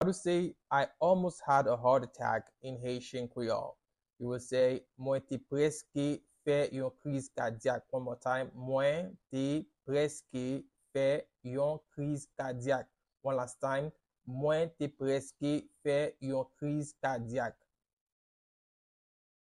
Listen to and watch “Mwen te prèske fè yon kriz kadyak” audio pronunciation in Haitian Creole by a native Haitian  in the video below:
I-almost-had-a-heart-attack-in-Haitian-Creole-Mwen-te-preske-fe-yon-kriz-kadyak-pronunciation.mp3